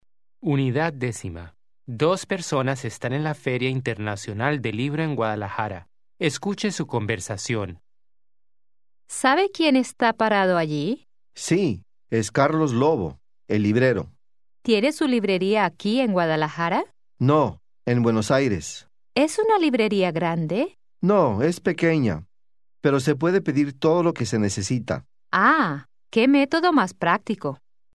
Аудио курс для самостоятельного изучения испанского языка.